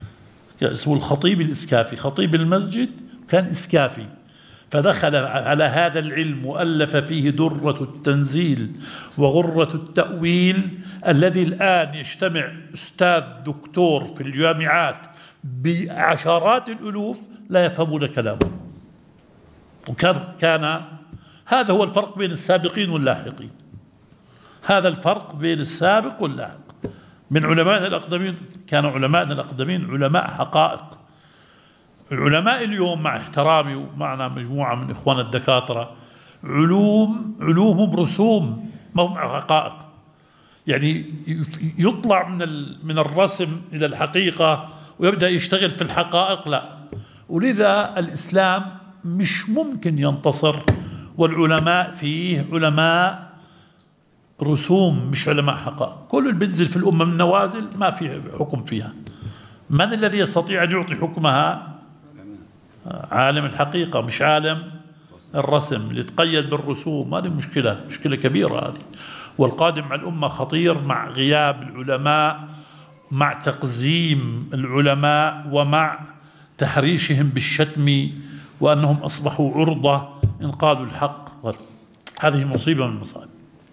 شرح مبحث المطلق والمقيد في أصول الفقه – الدرس الأول